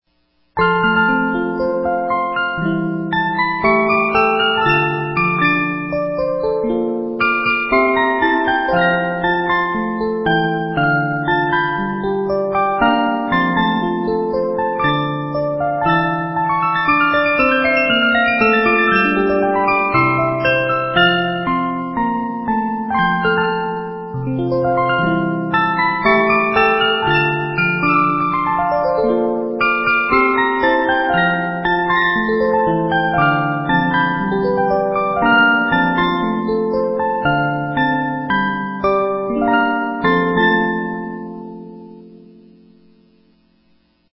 Music box Sound Bites of 50 note movements